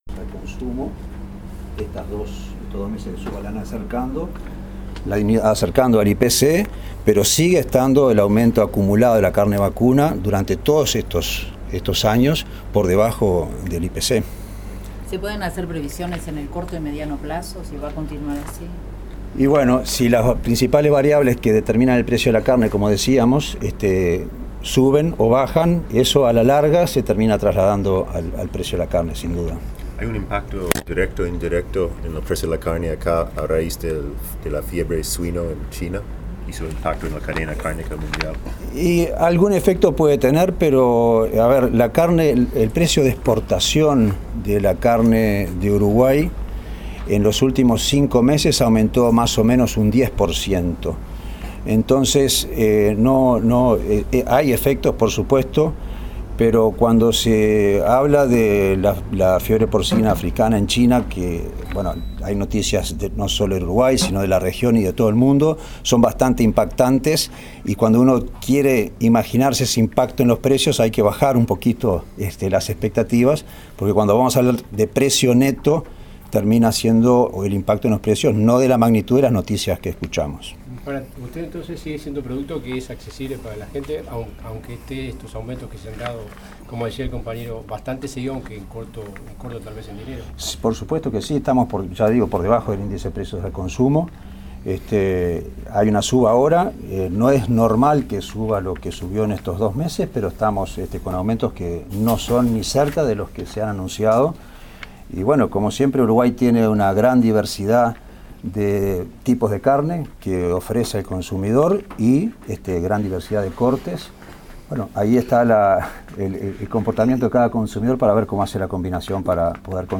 Entrevista al Presidente de INAC, Federico Stanham. mp3